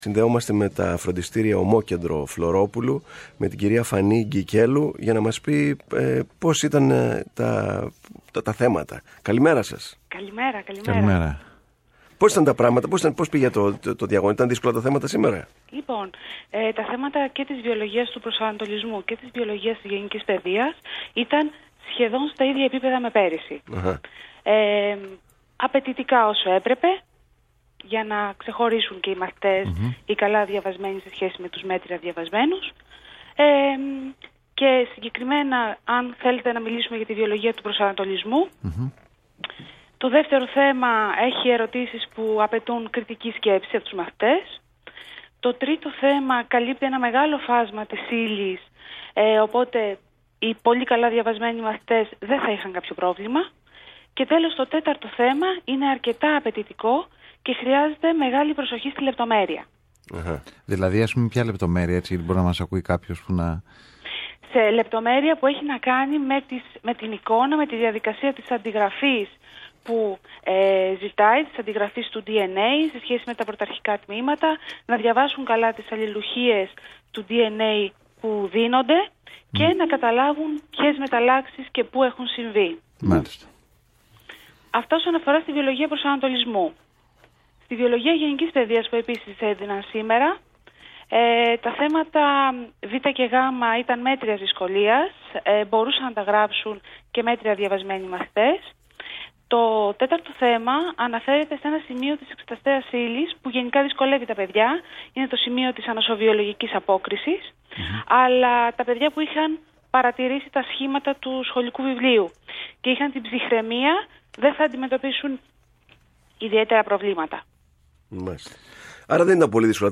ΣΧΟΛΙΑ ΣΤΟ ΒΗΜΑ FM ΓΙΑ ΤΗΝ ΒΙΟΛΟΓΙΑ ΓΕΝΙΚΗΣ ΠΑΙΔΕΙΑΣ ΚΑΙ ΤΗΝ ΒΙΟΛΟΓΙΑ ΠΡΟΣΑΝΑΤΟΛΙΣΜΟΥ ΑΠΟ ΤΑ ΦΡΟΝΤΙΣΤΗΡΙΑ ΦΛΩΡΟΠΟΥΛΟΥ